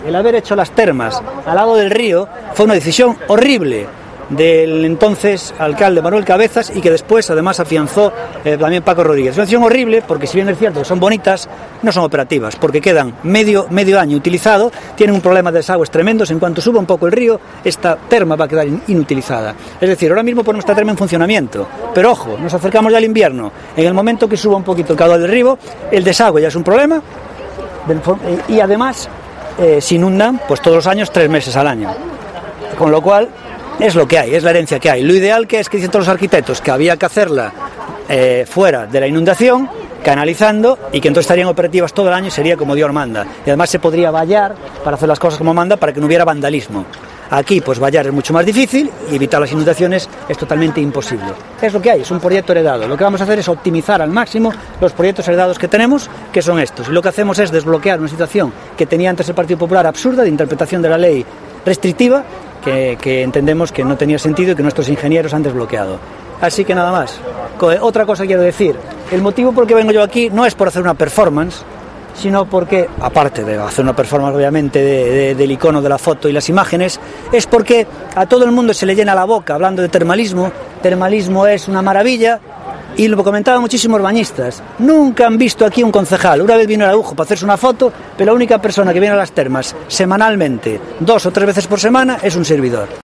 Declaraciones del alcalde de Ourense sobre las termas